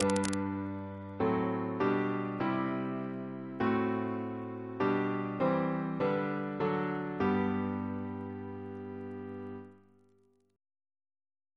Single chant in G Composer: Edwin George Monk (1819-1900), Organist of York Minster Reference psalters: H1940: 639